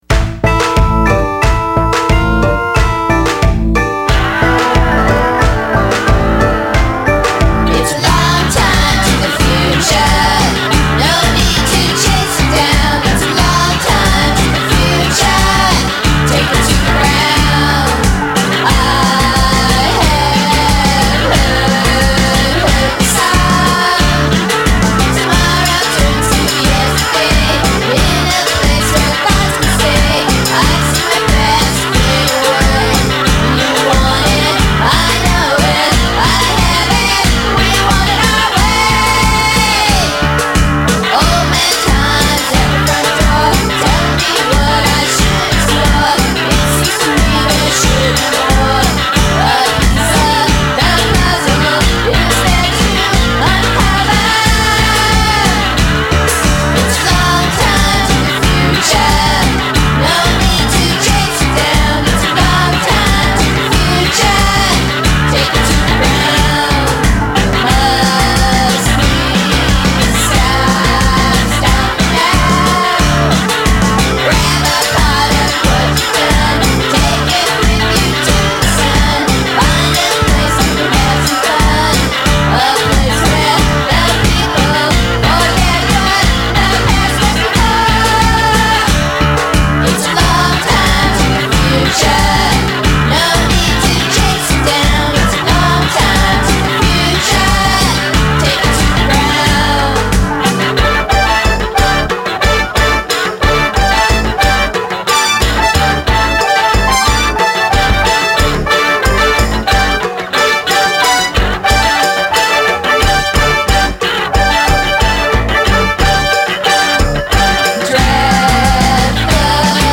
alternative pop rock music
drums and keyboards
guitars